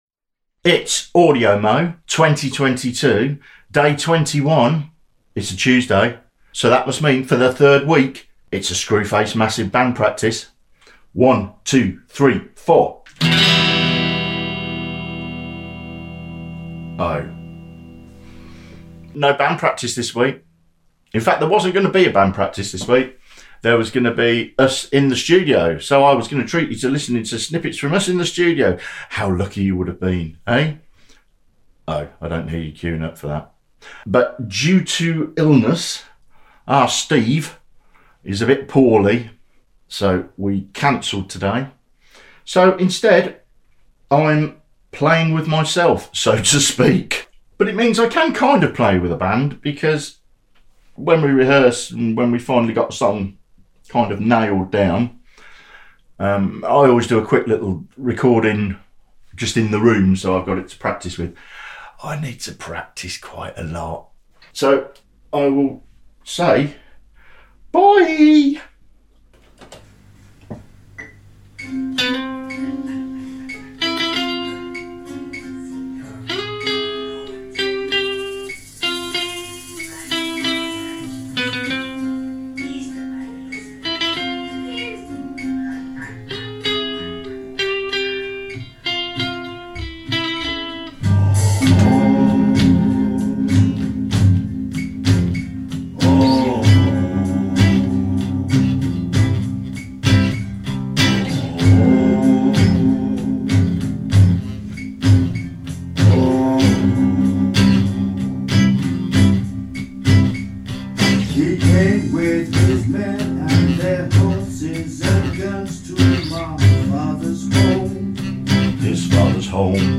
Not quite a band practice this week.